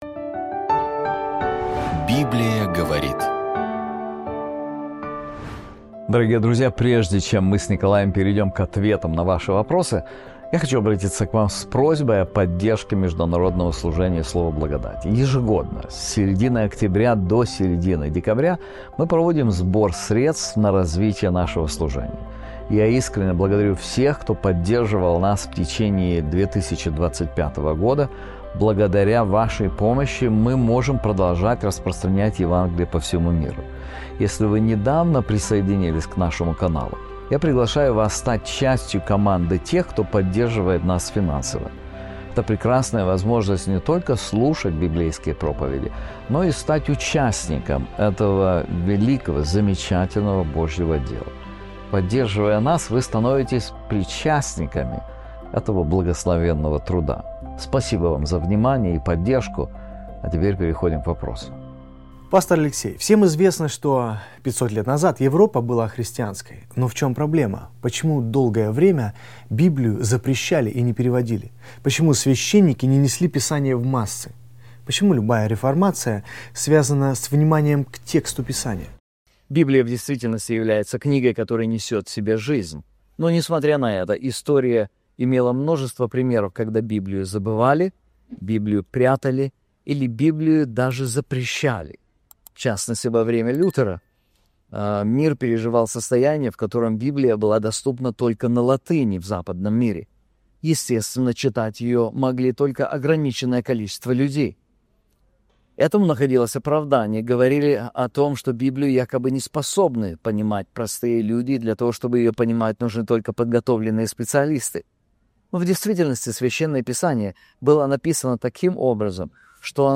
Записан в Виттенберге (Германия) - городе, где когда-то жил и трудился Мартин Лютер.